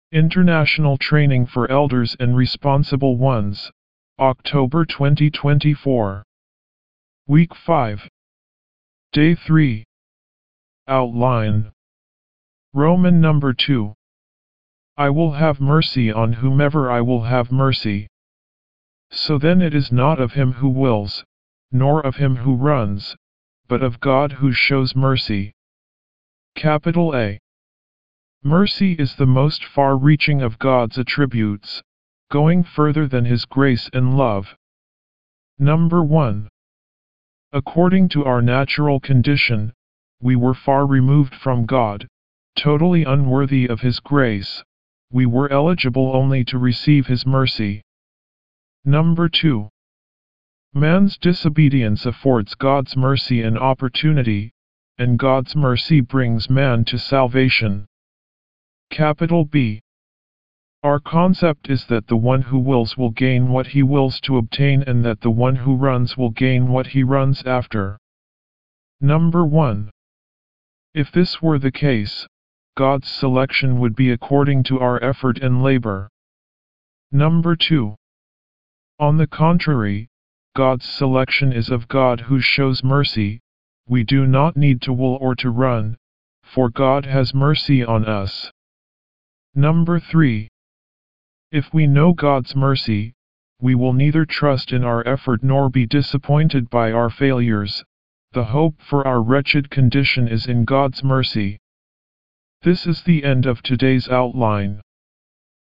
W5  Outline Recite
D3 English Rcite：